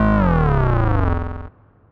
Misc Synth stab 06.wav